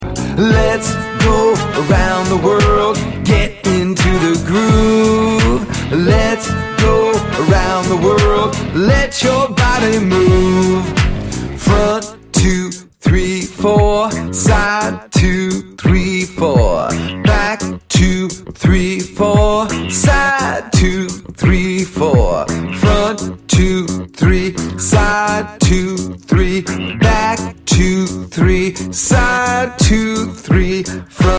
Song for Exercise and Fitness